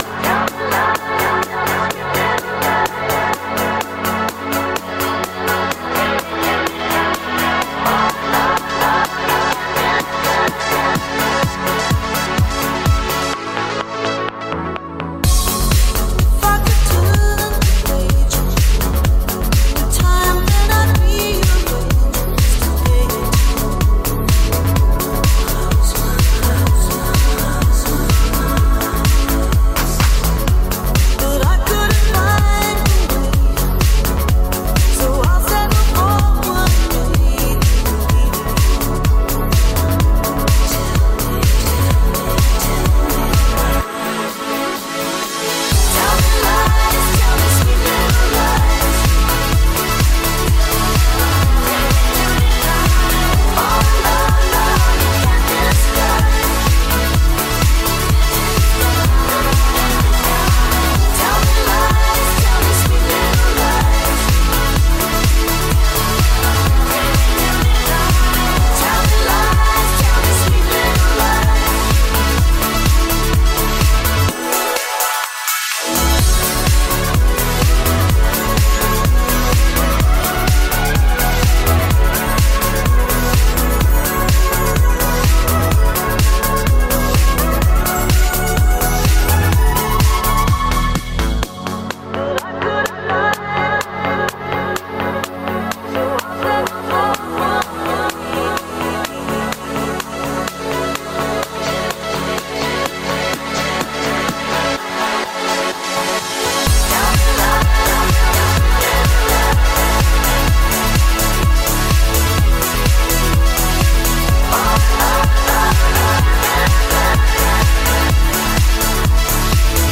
BPM126
Audio QualityPerfect (High Quality)
Trance house remix